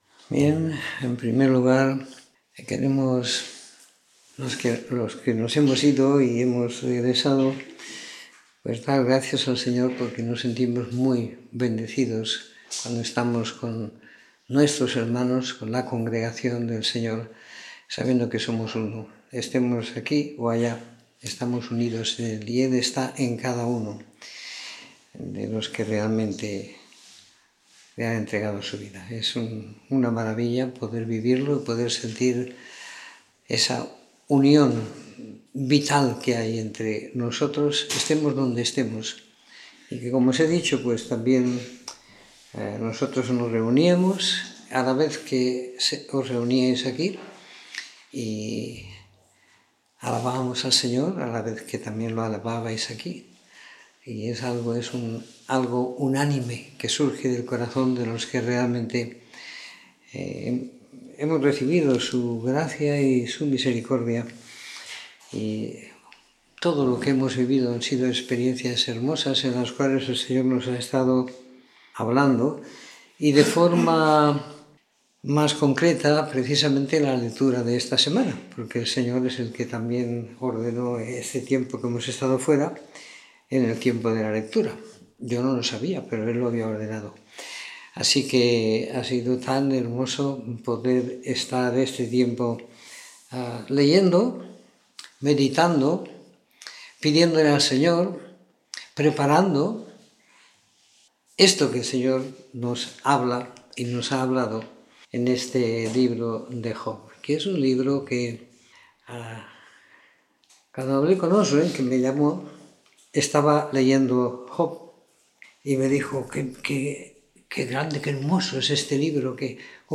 Comentario en el libro de Job, siguiendo la lectura programada para cada semana del año que tenemos en la congregación en Sant Pere de Ribes.